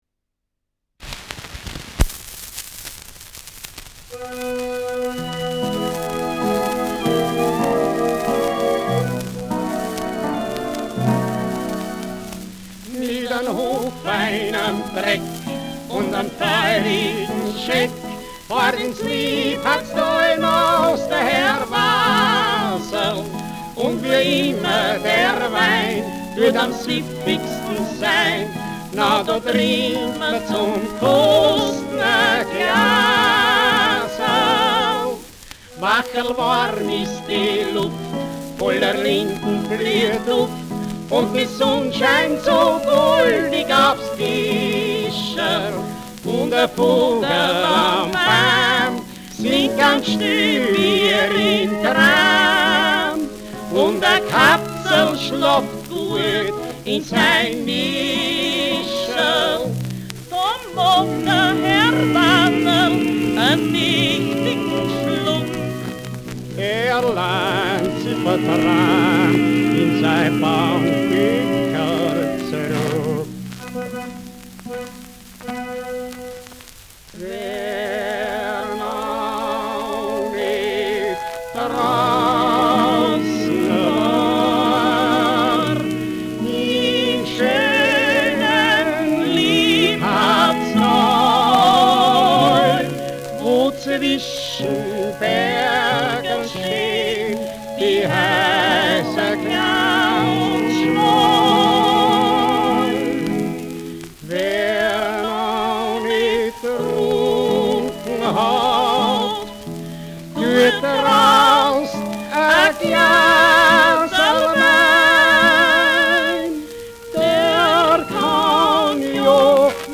Schellackplatte
Stärkeres Grundknistern : Vereinzelt leichtes Knacken
[Wien] (Aufnahmeort)